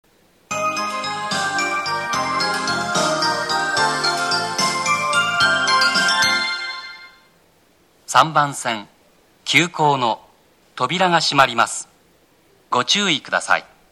3番線急行発車放送
moriguchishi-track3-d_ex.mp3